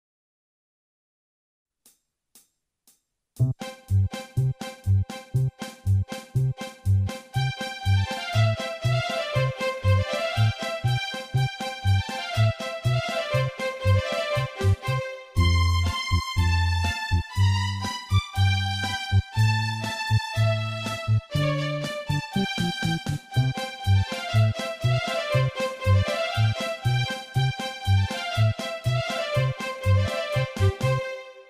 ちょっとシンセサイザーで弾いてみた。
なので、ワンコーラスだけ
宅録で作ってみた。（笑）